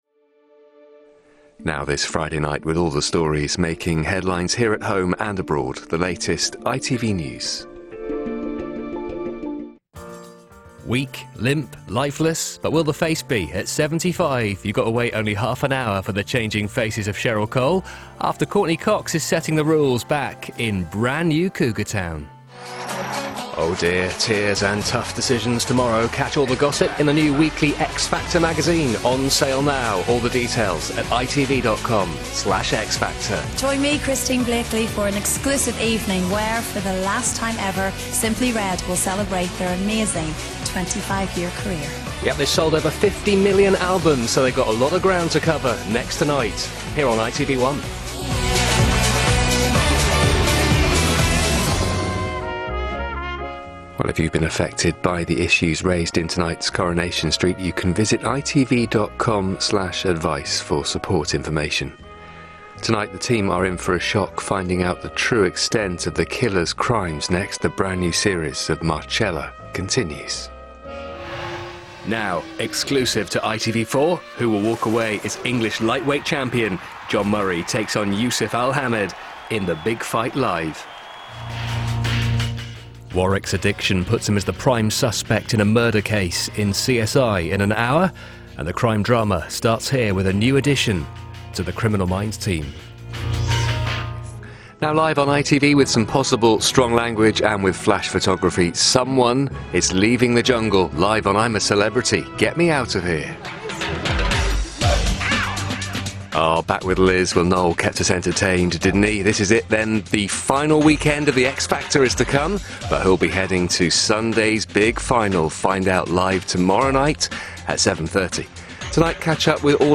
A familiar voice on ITV and the BBC.